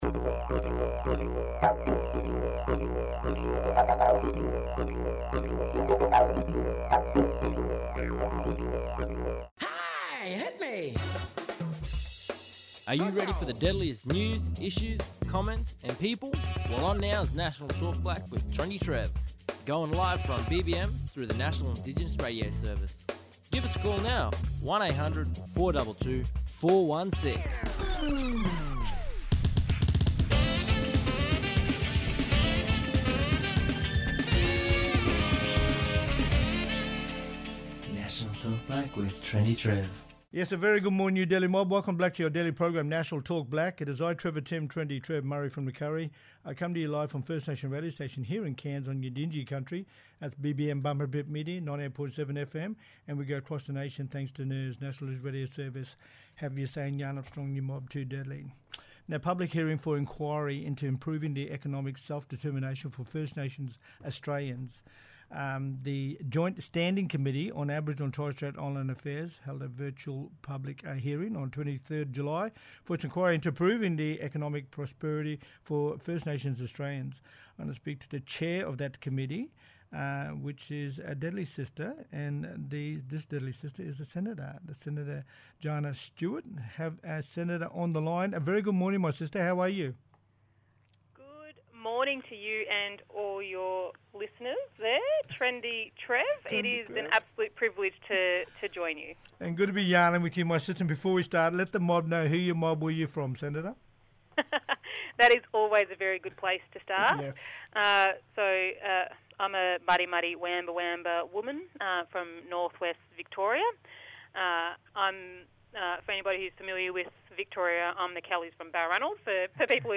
On today’s National Talk Black via NIRS – National Indigenous Radio Services we have:
Senator Jana Stewart, Chair of The Joint Standing Committee on Aboriginal and Torres Strait Islander Affairs, talking about the Public hearing for inquiry into improving the economic self-determination for First Nations Australians.